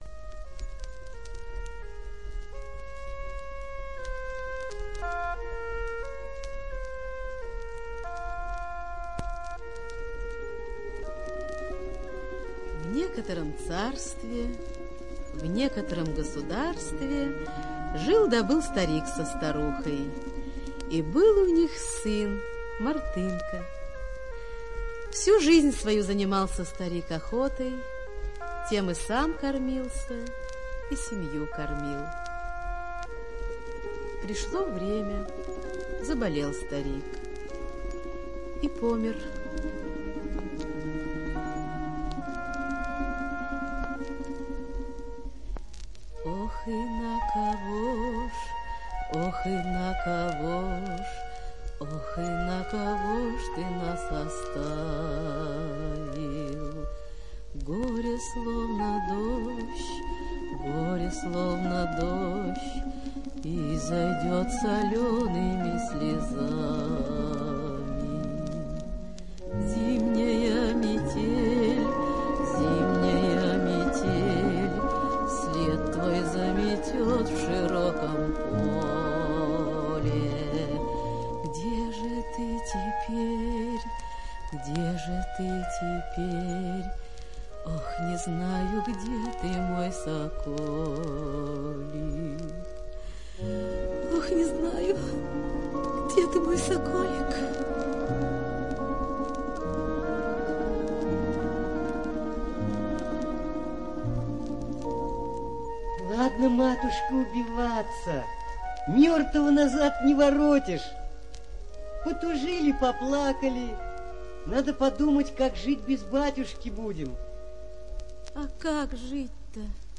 Волшебное кольцо - сборник аудиосказок - слушать онлайн